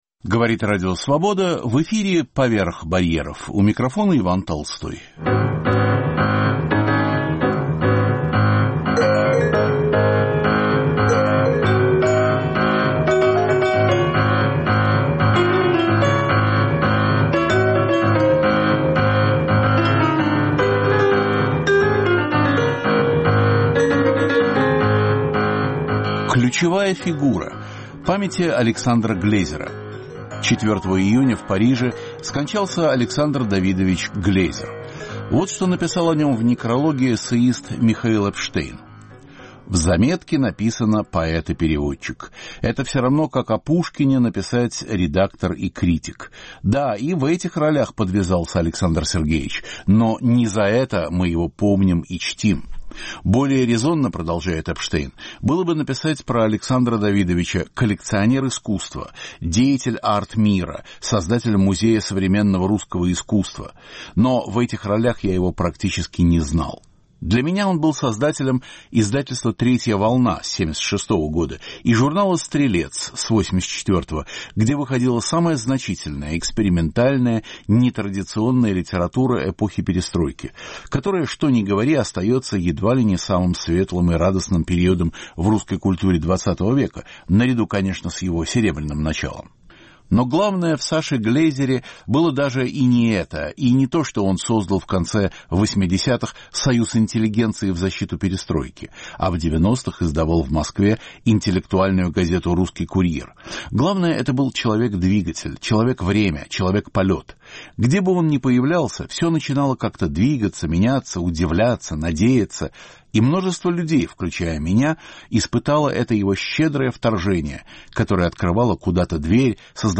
Памяти Александра Глезера – издателя, поэта, создателя нескольких музеев современной российской живописи во Франции и Америке. Архивные записи Свободы.